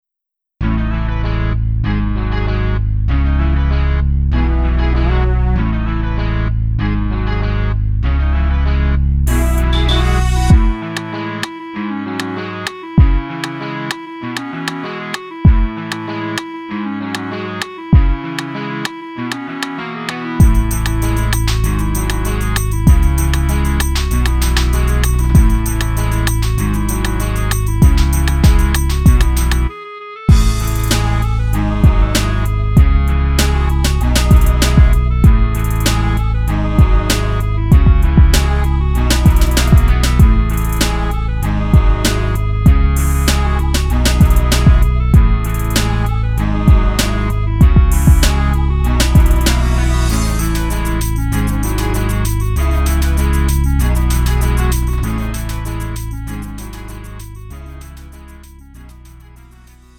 음정 -1키 3:05
장르 구분 Lite MR